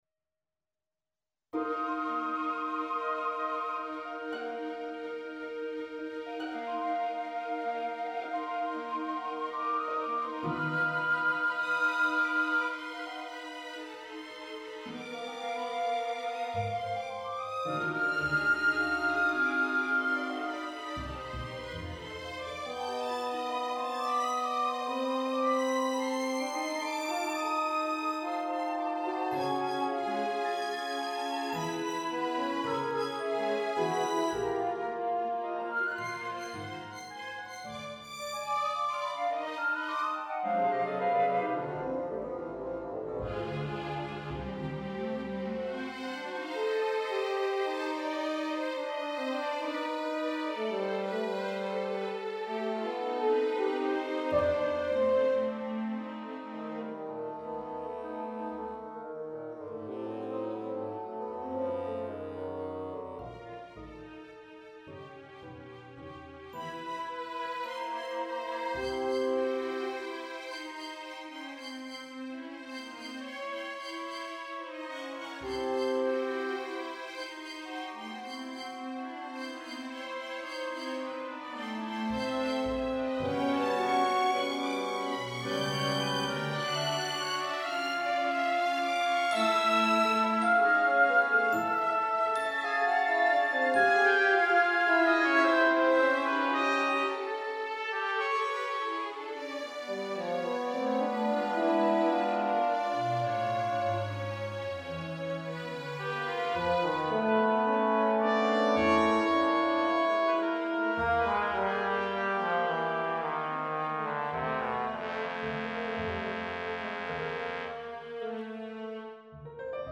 Symphony no.2 - symphony orchestra 2010-14
The former, an intermezzo, is light, open-sounding, undulating, and with a strong tonal feel; the latter is, by contrast, a dark, brooding, even at times disruptive scherzo.
Scoring: Piccolo, 2 Flutes, 2 Oboes, Cor Anglais, 2 Clarinets, Bass Clarinet, 2 Bassoons, Contrabassoon, 4 Horns, 2 Trumpets, 2 Trombones, Tuba, Timpani, Percussion (Triangle, Snare Drum, Bass Drum, Tubular Bells, Glockenspiel, Xylophone, Celeste), Piano, Harp, Strings
Computer generated realisation (..my apologies...)